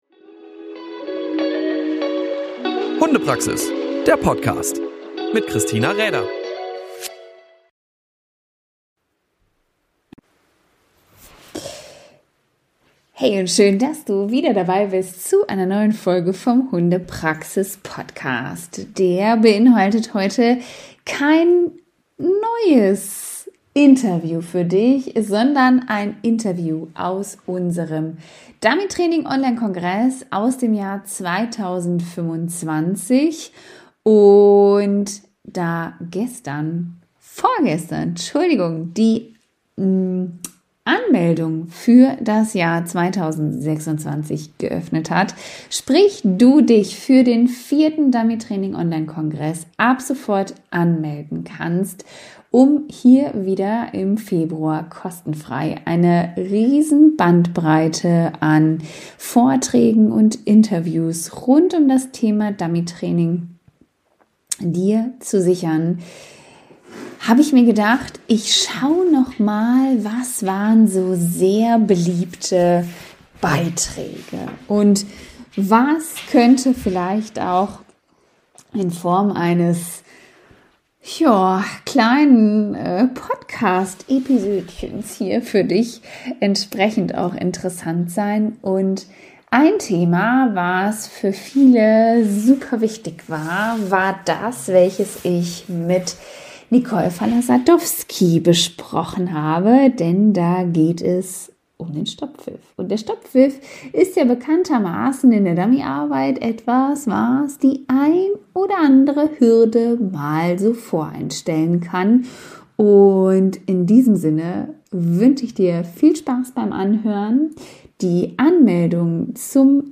Stopppfiff in der Dummyarbeit (Interview aus dem Dummytraining-Onlinekongress 2025) Seit gestern ist die Anmeldung für den 4.